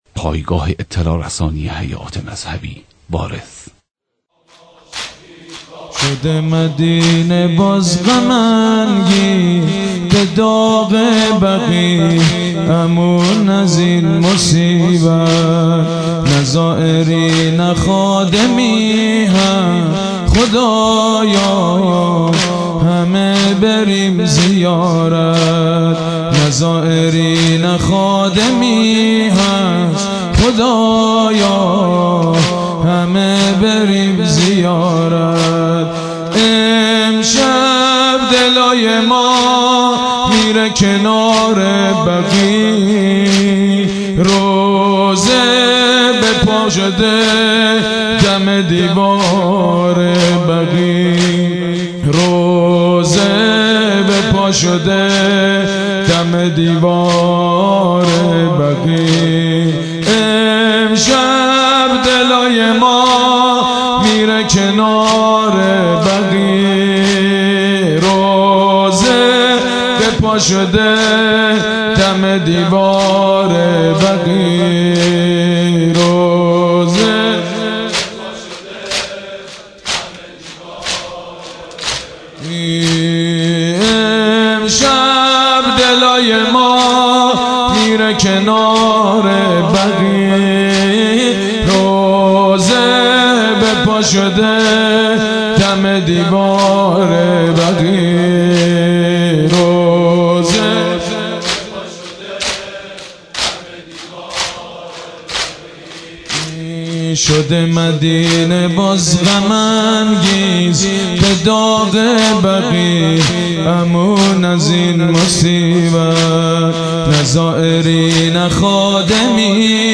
مداحی حاج سید مجید بنی فاطمه به مناسبت شهادت امام صادق (ع)